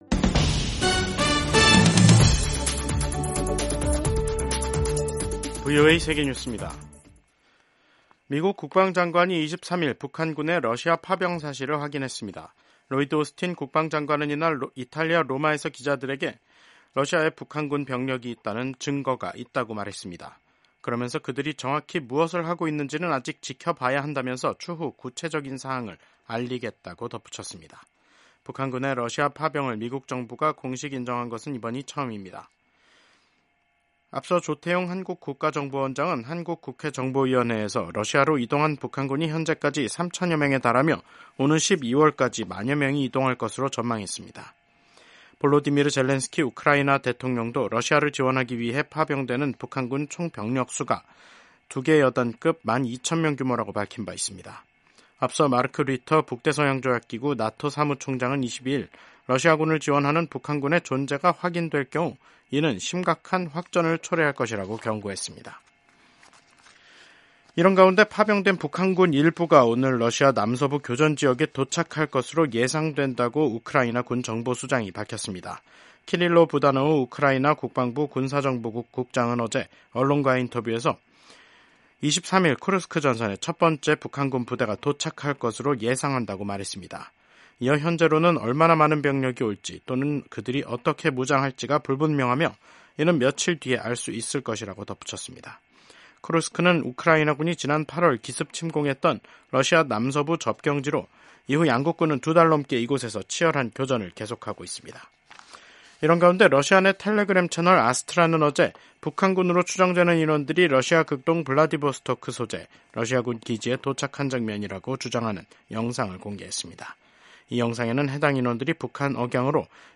세계 뉴스와 함께 미국의 모든 것을 소개하는 '생방송 여기는 워싱턴입니다', 2024년 10월 23일 저녁 방송입니다. 팔레스타인 가자지구 분쟁을 이제 끝내야 할 때라고 토니 블링컨 미국 국무장관이 강조했습니다. 미국 대선 선거 운동이 막바지 단계에 들어선 가운데 공화당 후보인 도널드 트럼프 전 대통령과 민주당 후보 카멀라 해리스 부통령이 서로를 겨냥한 공방을 이어갔습니다.